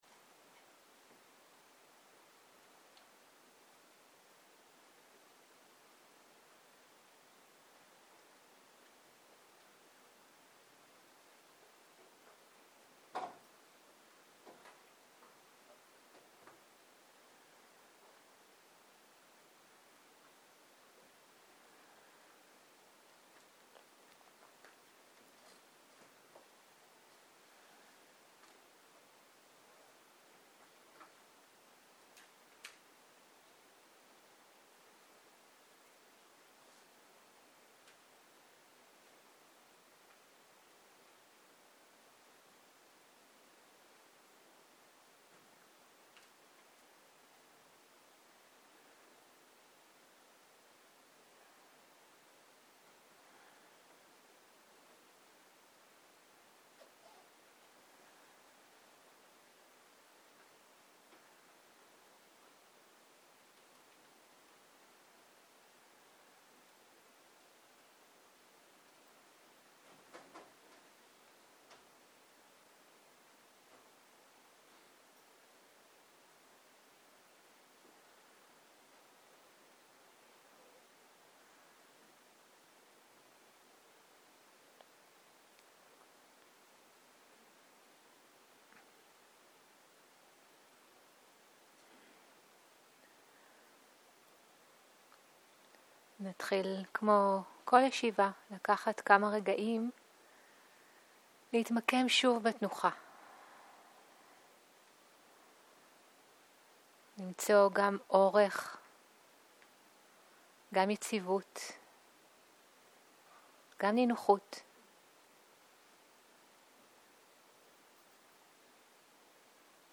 Dharma type: Guided meditation